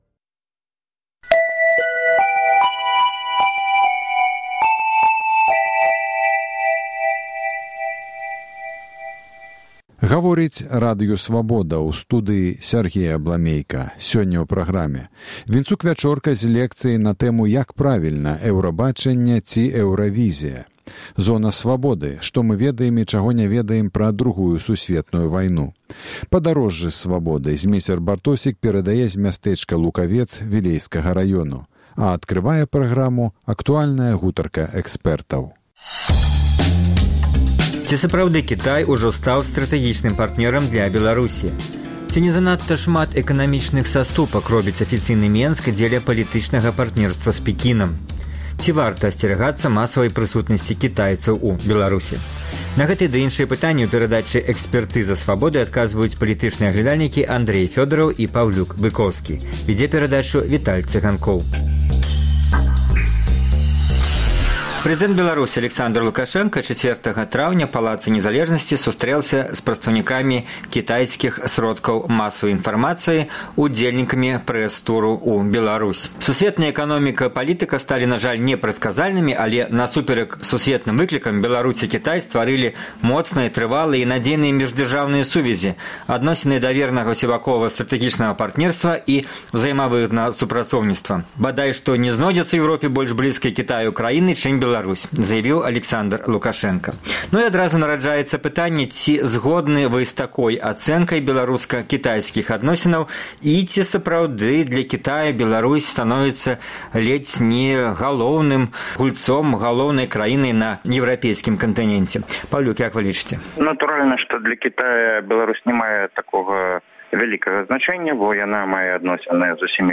палітычныя аглядальнікі